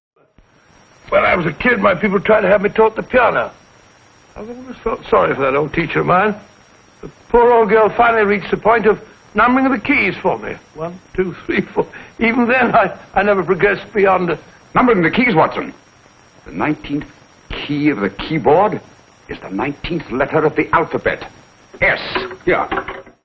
Three Music Boxes